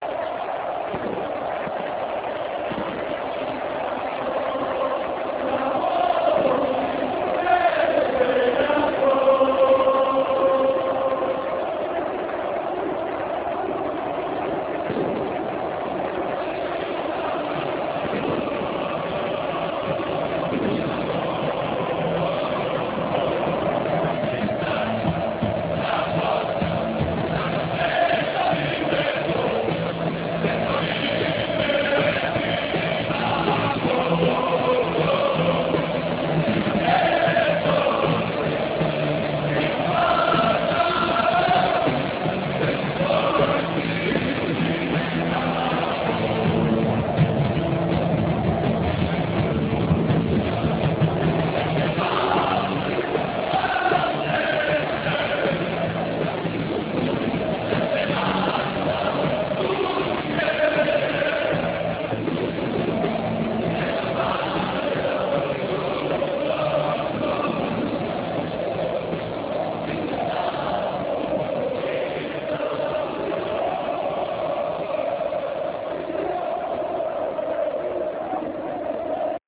Snimke od kojih je nastao film napravljene su digitalnim fotoaparatom, za vrijeme utakmica. Kvaliteta tih snimaka je loša zbog specifičnih uvjeta, te je to dodatno umanjilo kvalitetu samog filma.